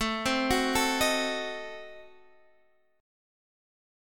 Adim7 chord